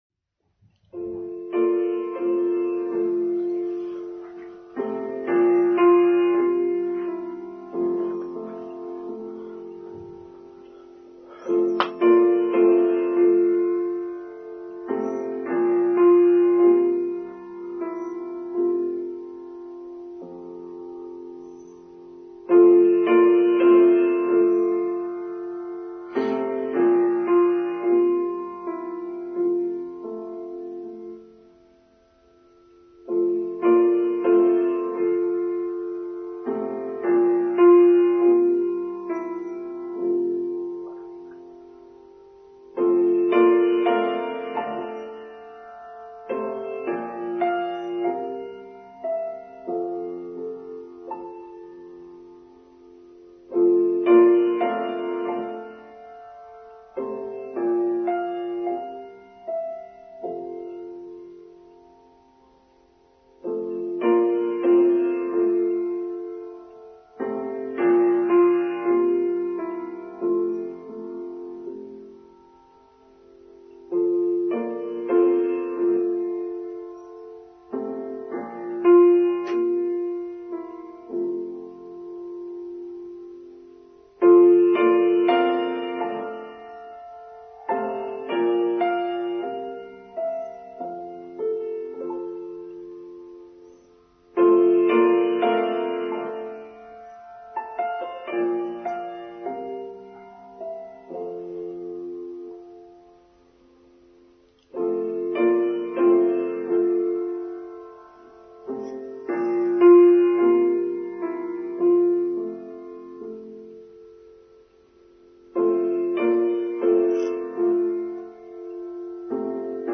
Kindsight – Online Service for Sunday 25th September 2022